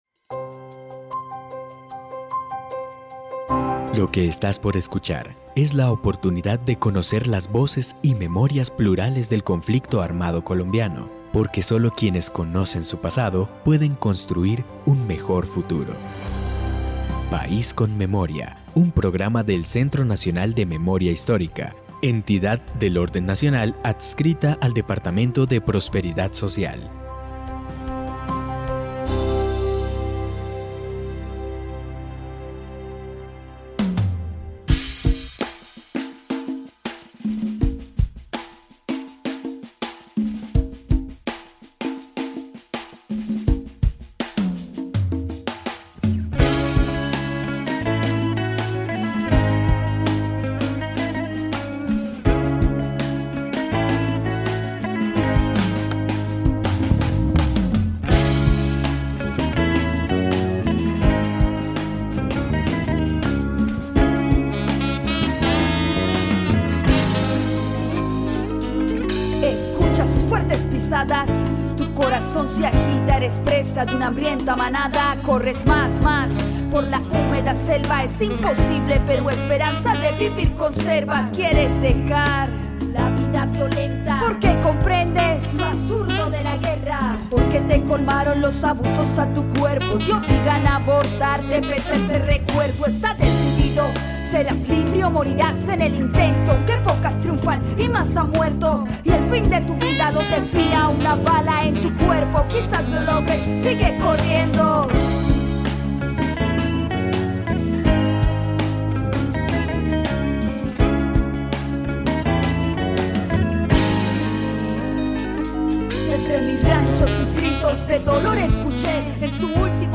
Testimonios.
Hoy en País con Memoria hablamos con algunas de las protagonistas de estos relatos.
Descripción (dcterms:description) Capítulo número 17 de la cuarta temporada de la serie radial "País con Memoria". Testimonios de mujeres víctimas de reclutamiento forzado, violencia sexual y basada en género por parte de las FARC-EP.